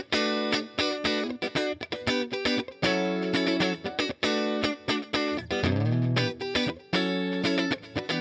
11 Rhythm Guitar PT2.wav